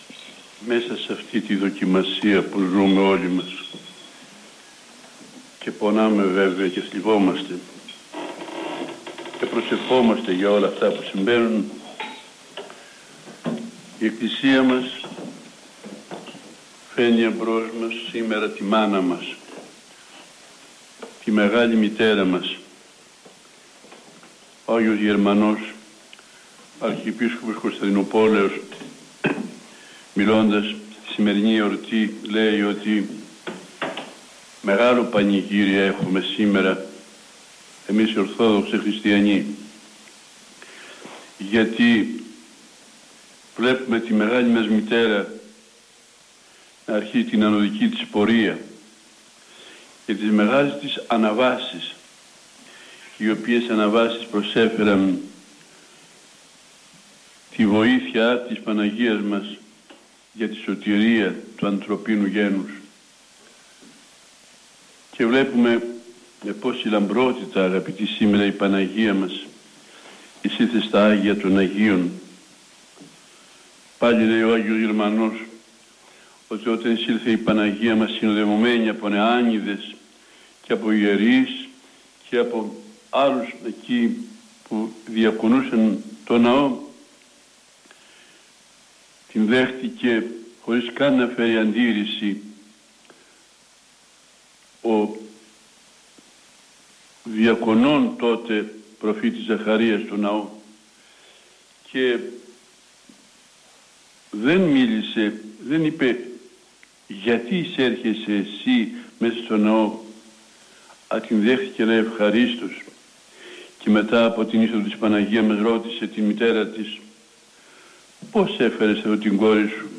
Η πανήγυρη της Ιεράς Μονής εφέτος ήταν διαφορετική, λόγω των περιοριστικών υγειονομικών μέτρων, που δεν επέτρεψαν την προσέλευση των πιστών.
Ακούστε το κήρυγμα του Σεβ. Μητροπολίτη Αιτωλίας και Ακαρνανίας κ.κ. Κοσμά
Κηρυγμα-Σεβασμιώτατου-Μητροπολίτου-Αιτωλίας-και-Ακαρνανίας-κ.κ.Κοσμά-Θ.Λειτουργία-Εισοδίων-της-Θεοτόκου-2020.mp3